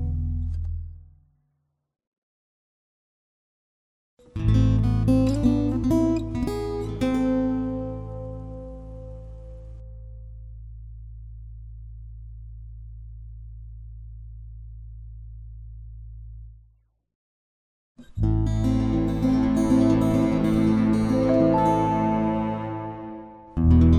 With Gap Removed Duets 2:22 Buy £1.50